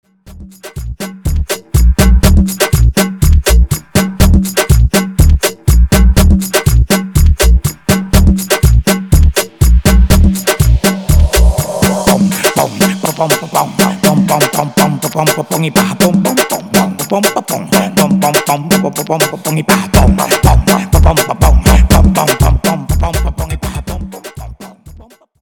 Extended Dirty Intro